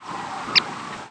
Longspur rattles
McCown's Longspur ~5.7 kHz ~28 mS
The descending individual notes seem to run together and give the rattle a squeaky quality. McCown's seems to rarely have more than 4 notes per rattle.